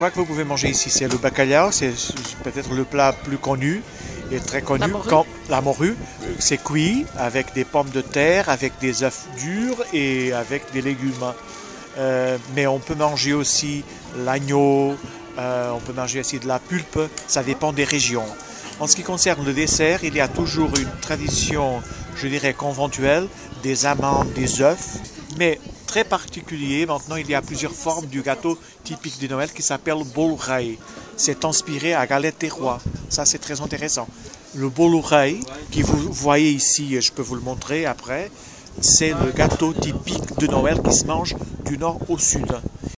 Marché de Noël de Montbéliard
Il faisait un vent glacial ce soir là. Vous allez avoir l’occasion de l’entendre !